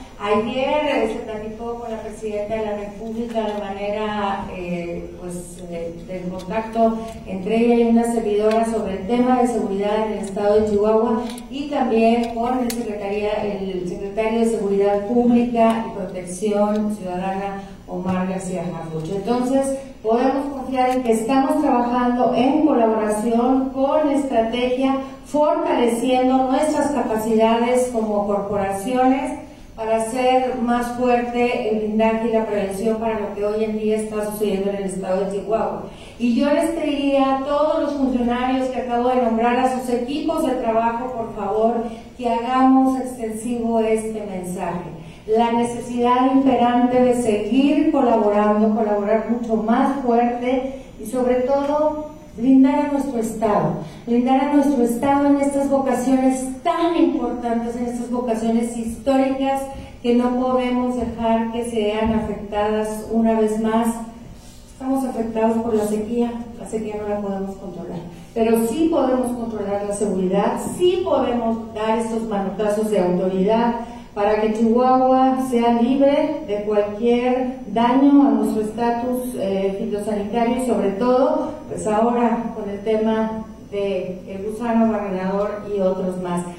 Durante la presentación del nuevo Grupo Estatal contra el Abigeato y el Fraude Agropecuario, la mandataria dedicó unas palabras al Gobierno Federal, un día después de que se reuniera con Sheinbaum, su gabinete y los gobernadores del país.